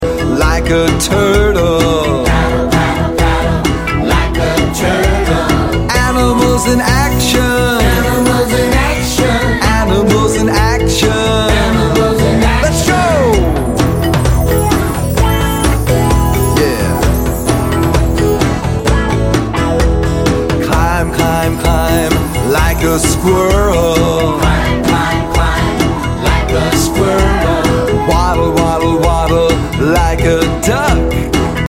Song for Exercise and Fitness